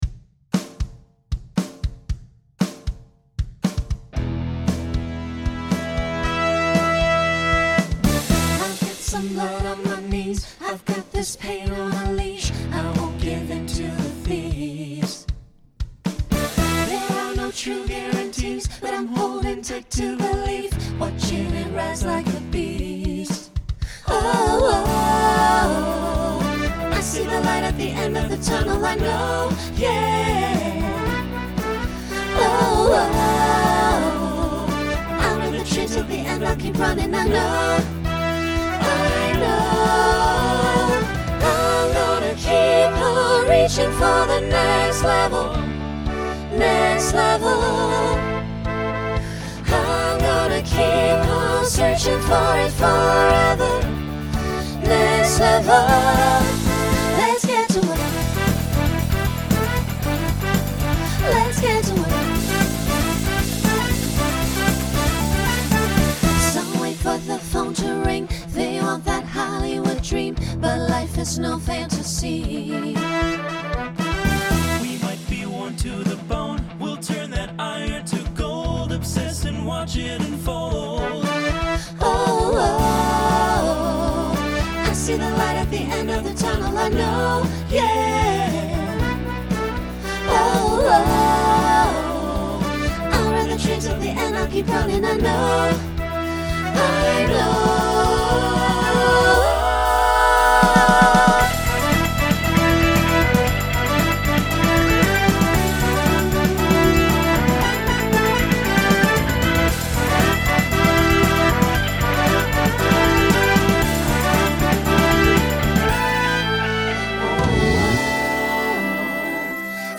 Genre Rock Instrumental combo
Opener Voicing SATB